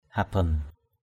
/ha-bʌn/ (d.) đồng = cuivre. salaw haban xl| hbN mâm đồng. karah haban krH hbN nhẫn đồng.
haban.mp3